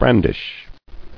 [bran·dish]